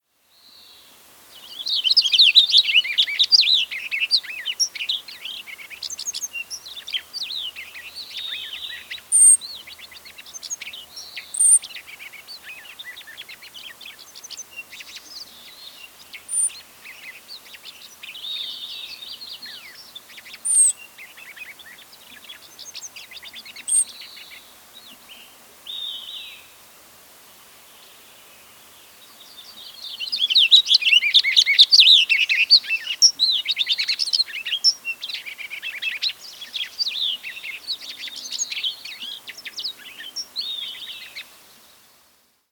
Cassin's Finch
How they sound: They sing a warbling, rollicking song that includes mimicked calls of other birds.